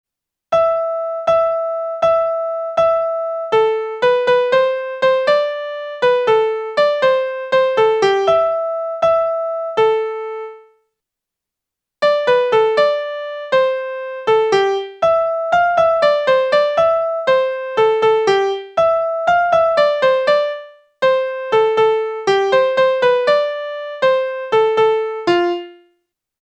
The result is below, and we see and hear that the result is not convincing as music.